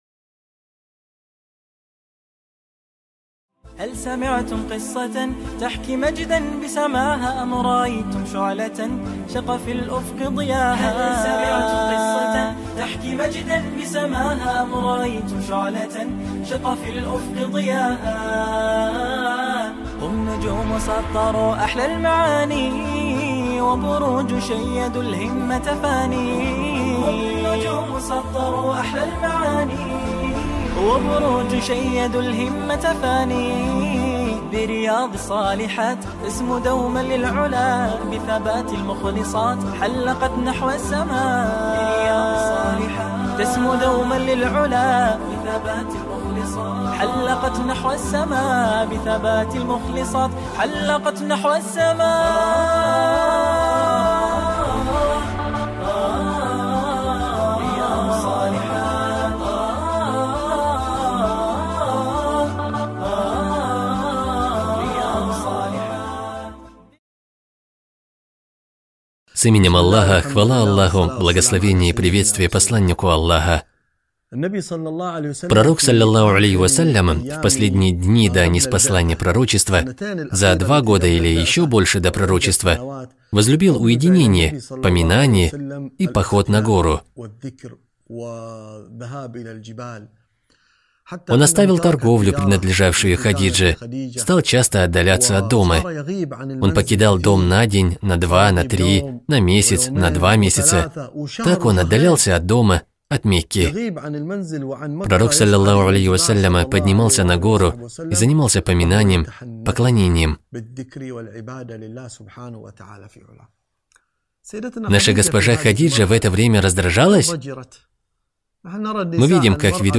Совершенные женщины | Цикл уроков для мусульманок